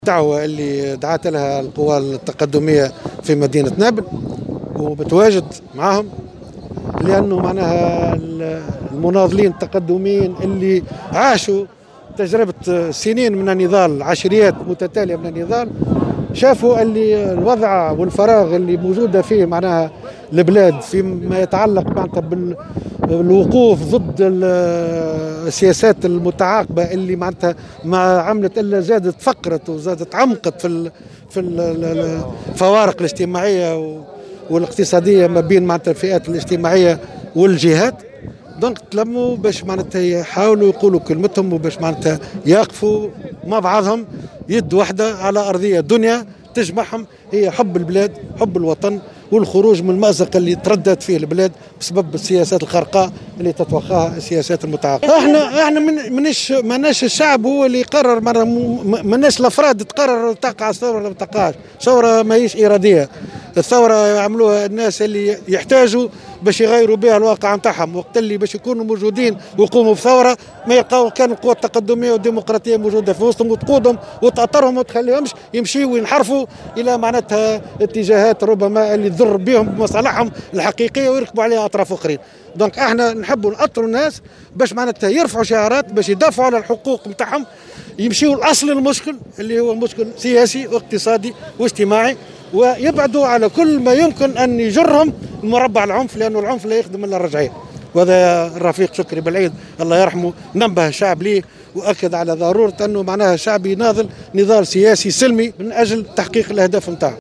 في تصريح لمراسلة الجوهرة أف أم